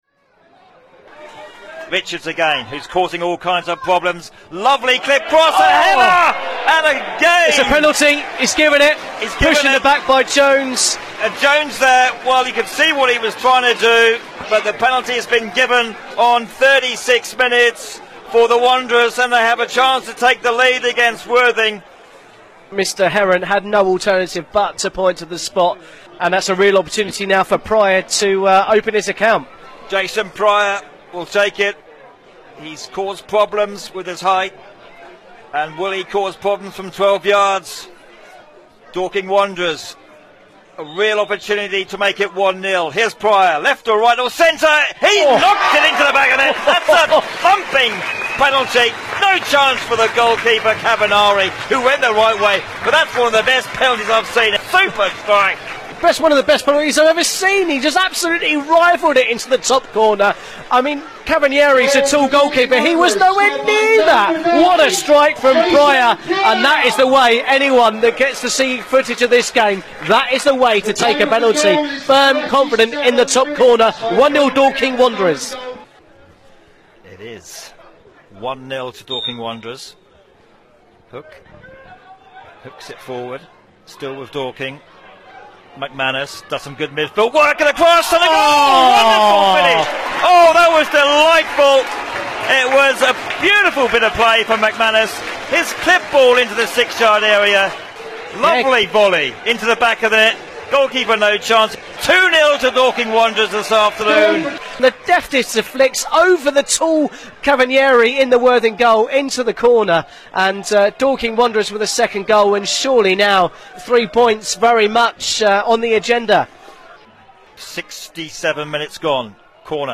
Highlights